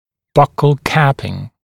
[ˈbʌkl ‘kæpɪŋ][ˈбакл ‘кэпин]окклюзионные накладки, накладки на зубы бокового сегмента для разобщения прикуса